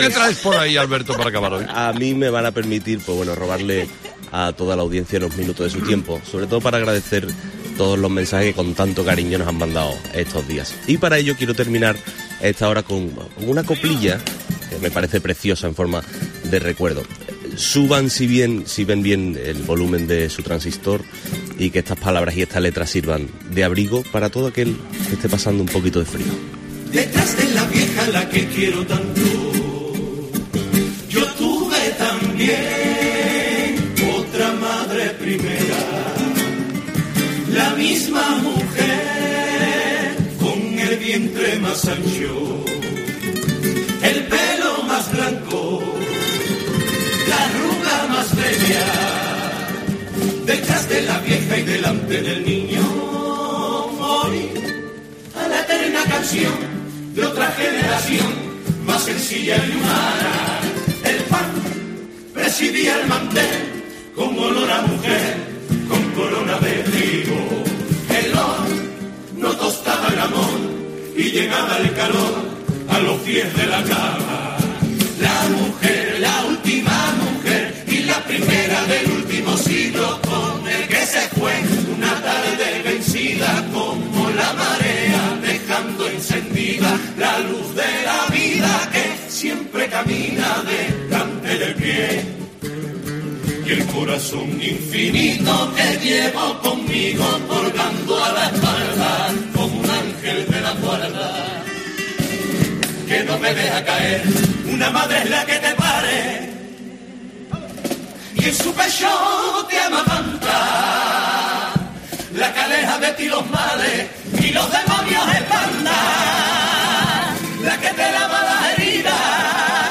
Lo que sí ha dejado claro es que parte del éxito de la tortilla de su abuela residía, precisamente en ponerle cebolla: "Pero una tortilla de cebolla tan rica... y un pollo con ciruelas, exquisito", ha querido añadir en el recuerdo que le ha brindado a su abuela en la radio.